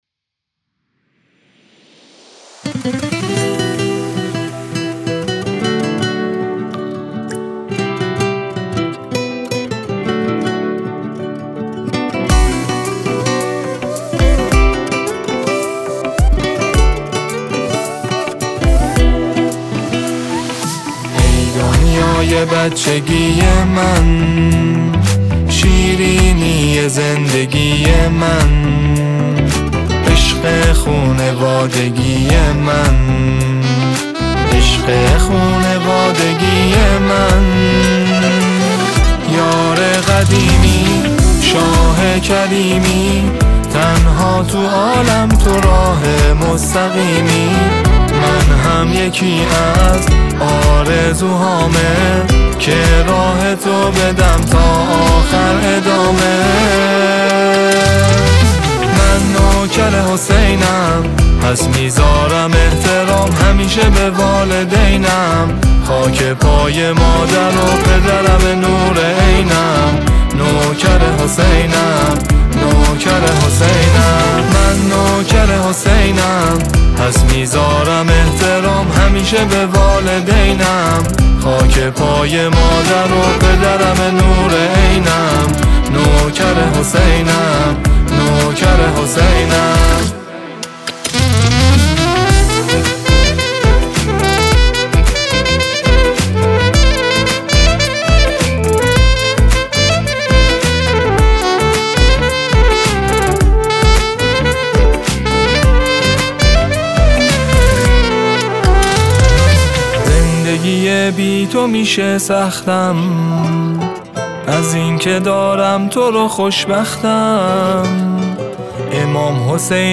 با نوایی دلنشین